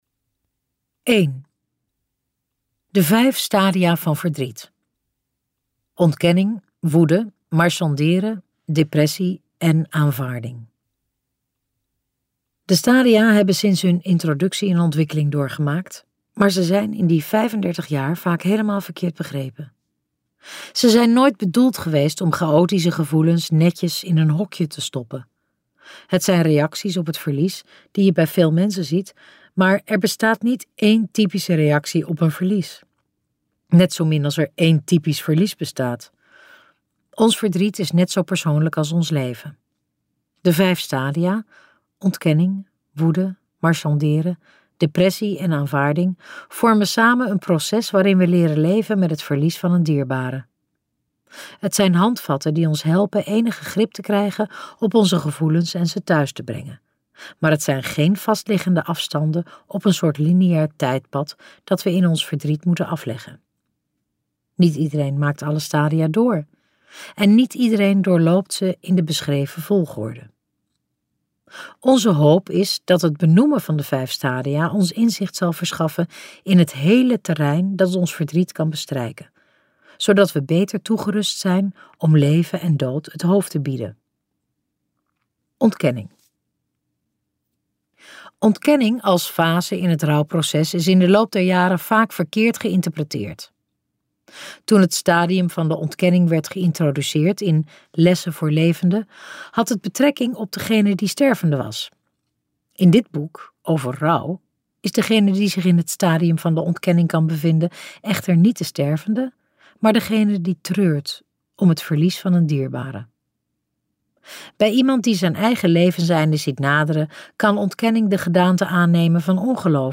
Ambo|Anthos uitgevers - Over rouw luisterboek